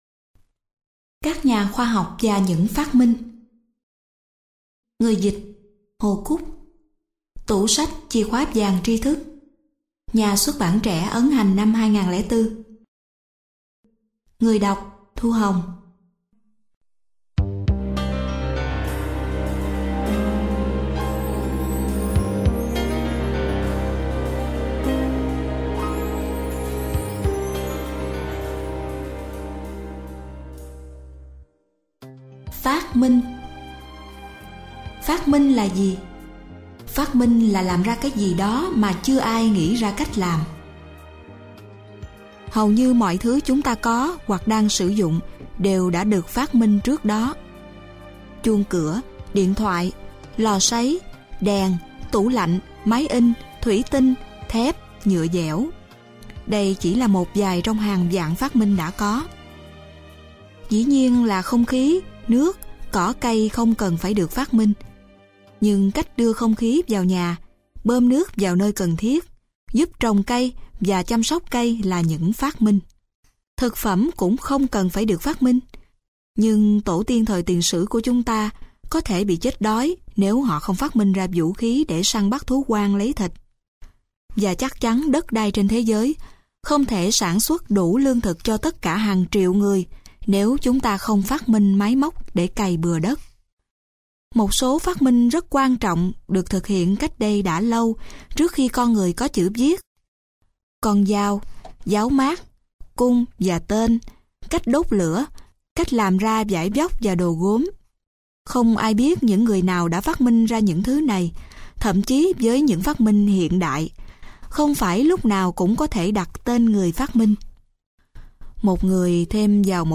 Sách nói Các Nhà Khoa Học Và Những Phát Minh - Sách Nói Online Hay